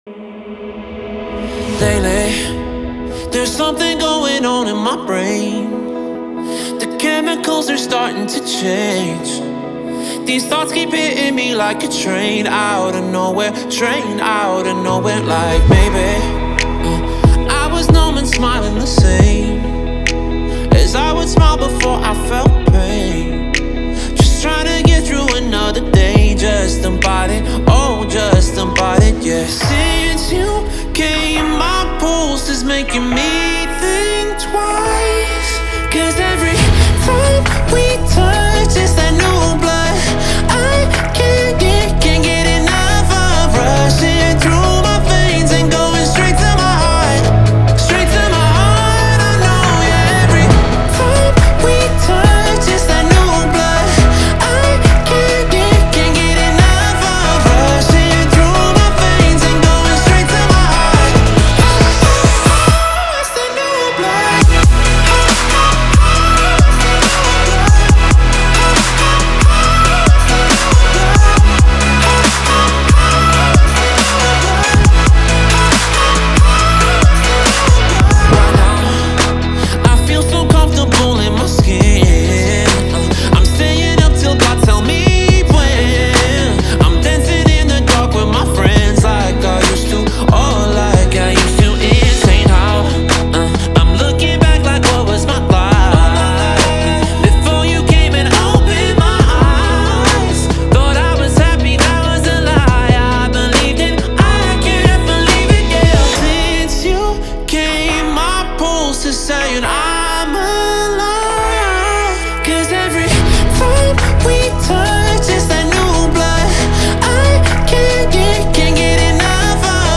энергичная электронная композиция